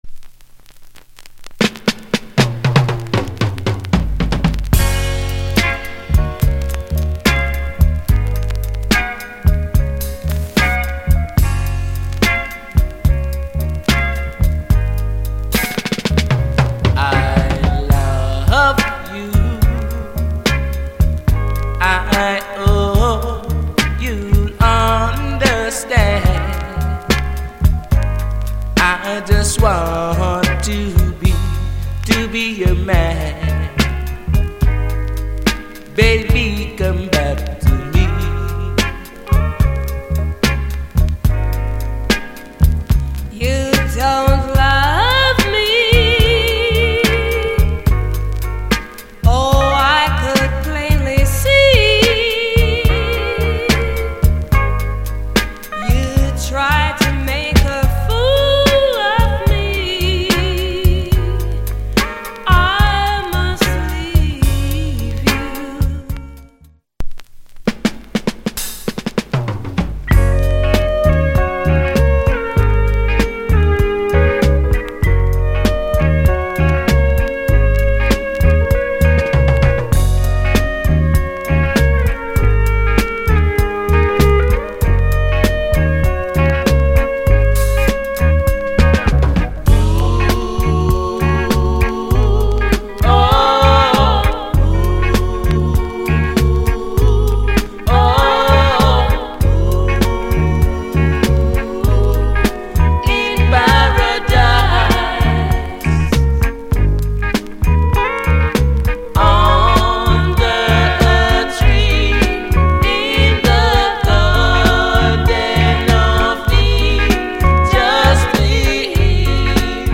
Genre Soul/R&B Ballad / Male Vocal Female Vocal